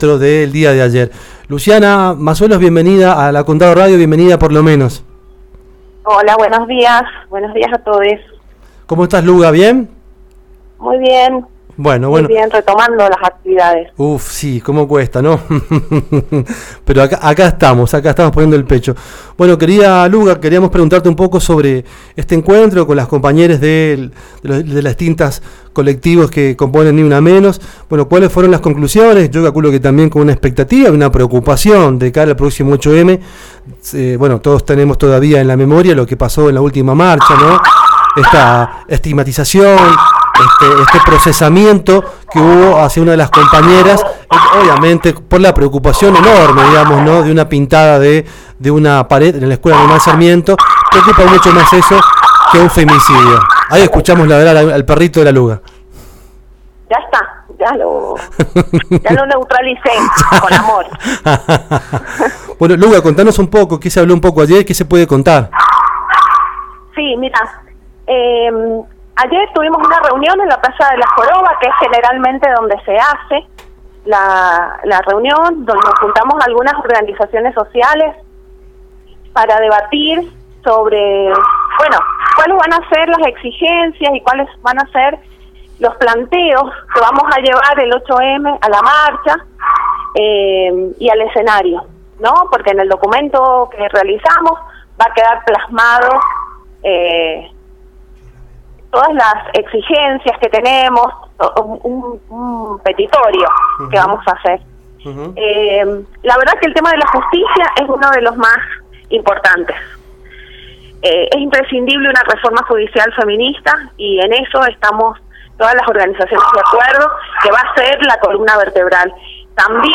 De todo eso se dialogó hoy en Condado Radio